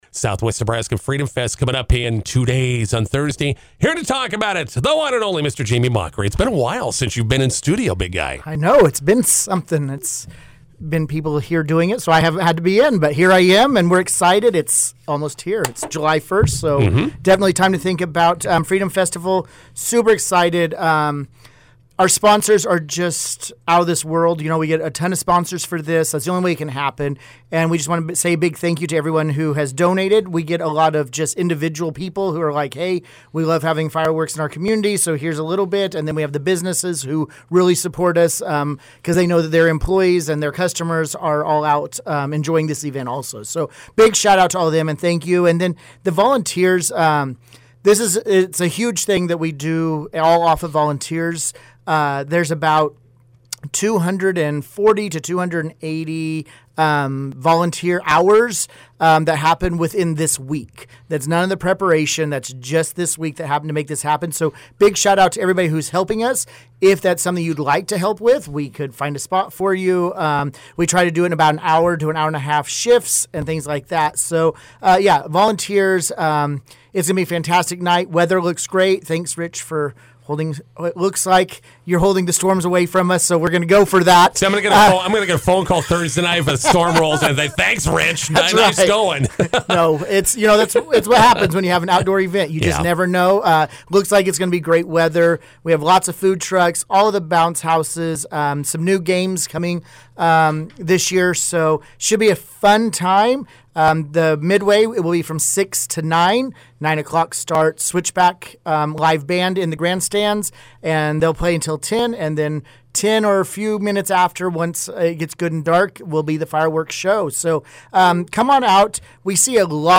INTERVIEW: Southwest Nebraska Freedom Fest is back this Thursday night in McCook.